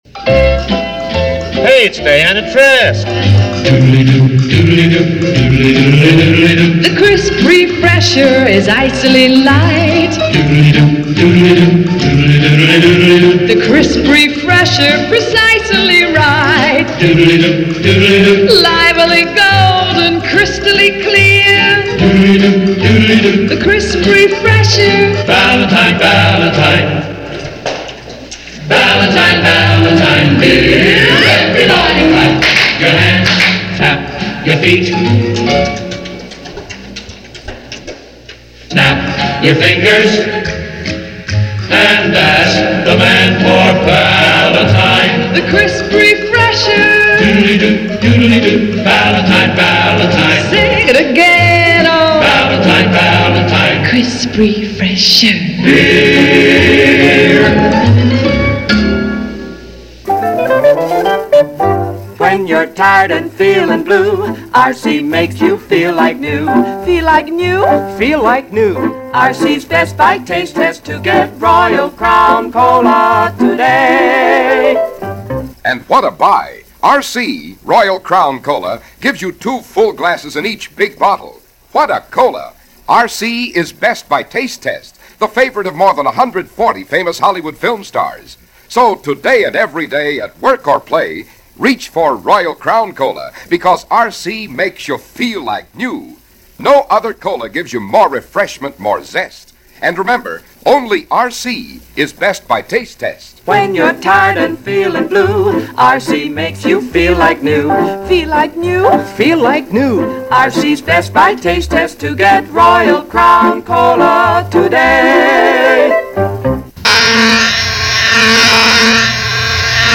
Radio Commercials Of The 1950s